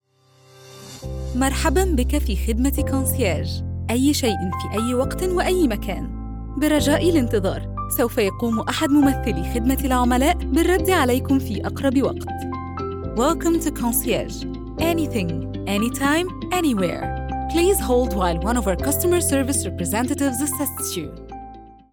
Kommerzielle Demo
IVR